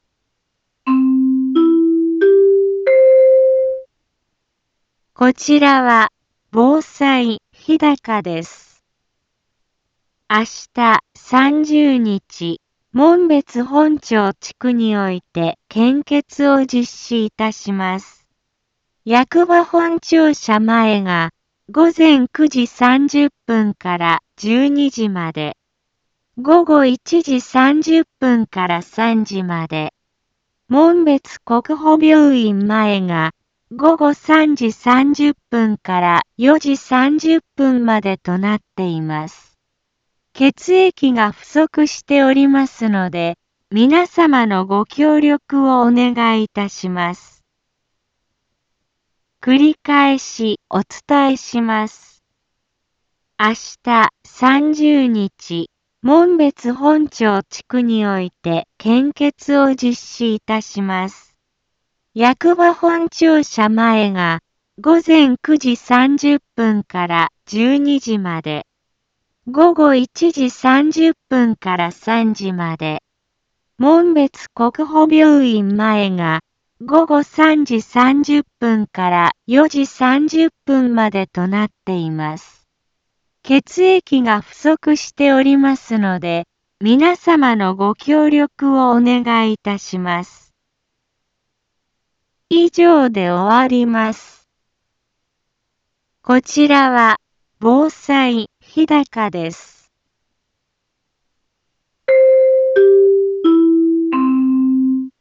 一般放送情報
Back Home 一般放送情報 音声放送 再生 一般放送情報 登録日時：2025-05-29 15:03:44 タイトル：献血広報 インフォメーション： こちらは、防災日高です。